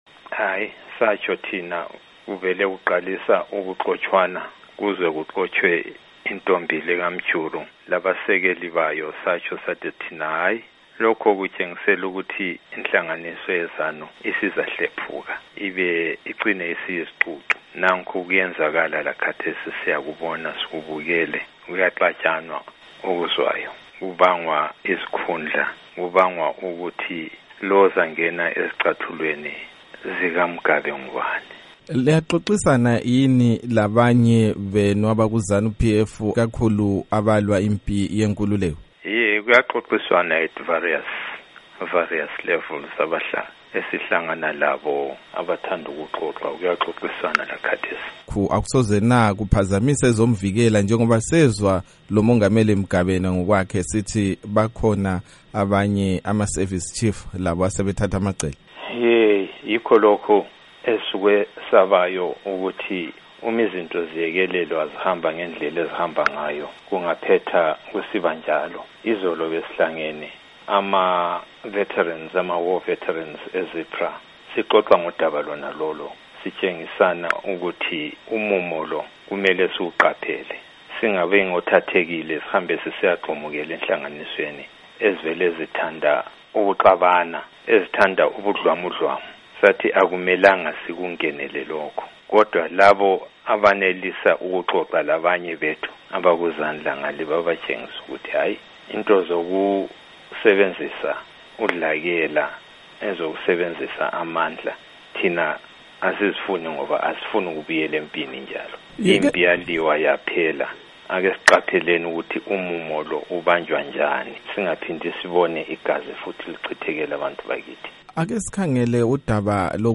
Ingxoxo loMnu. Dumiso Dabengwa